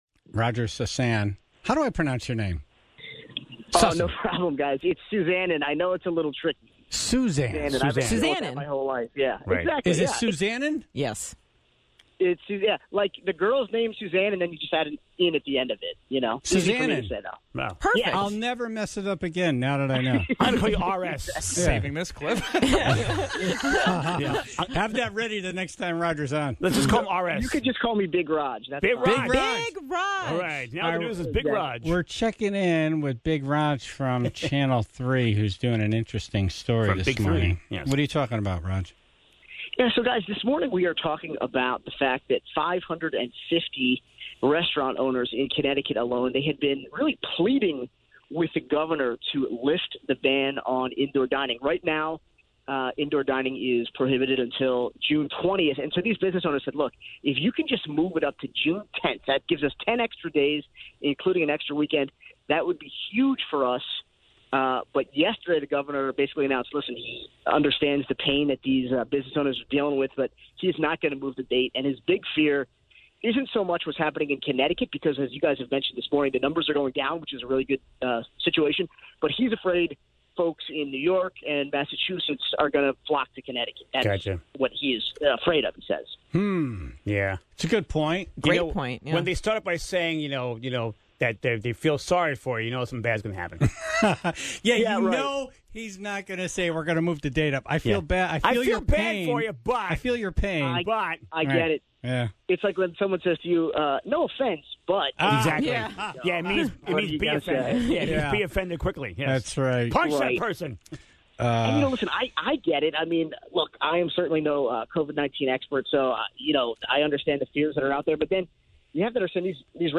(8:01) Comedian Chuck Nice was on the phone for Winner and/or Loser of the Week, but first addressed the George Floyd protests, police brutality, growing up black near Philadelphia, and made an incredible analogy for those still struggling to understand that not all police officers are being targeted.